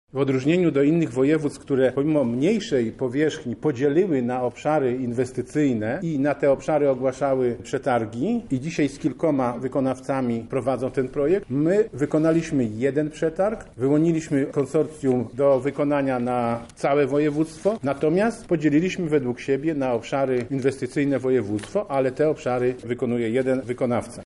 O szczegółach inwestycji mówi Sławomir Sosonowski, Marszałek Województwa Lubelskiego